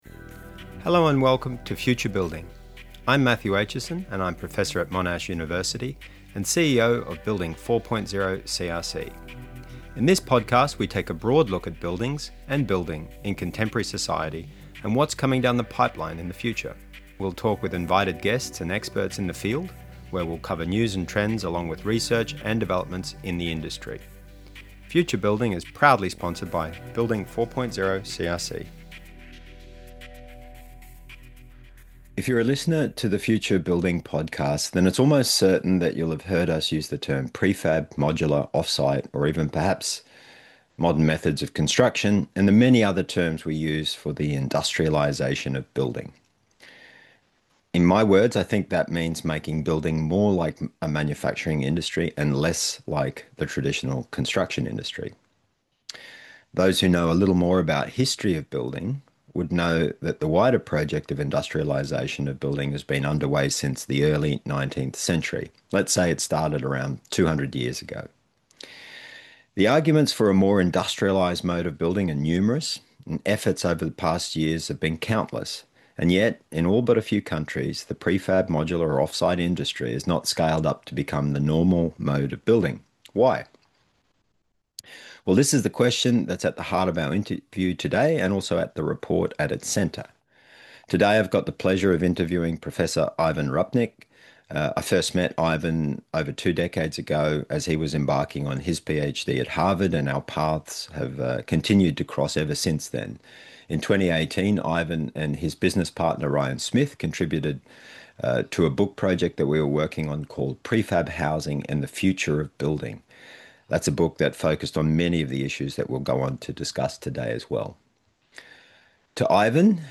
interviews with experts